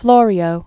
(flôrē-ō), John or Giovanni 1553?-1625.